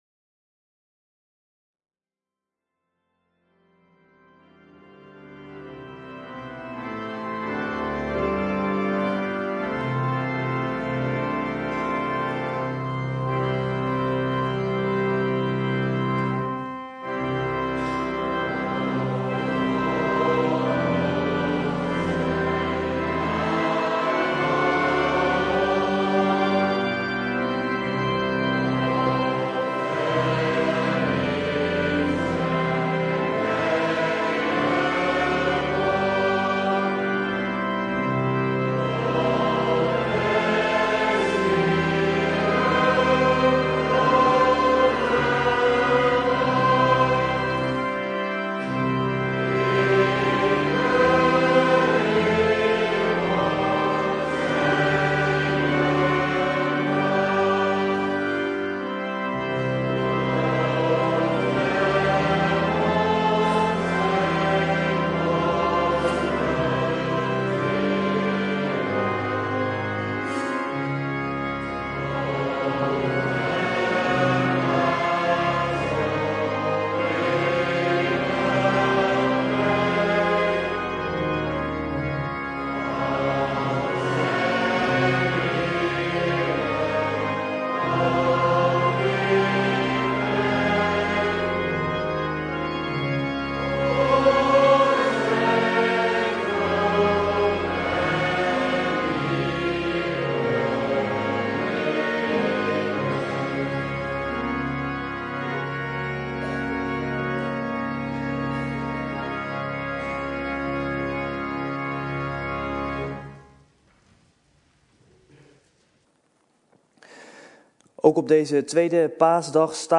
2e Paasdag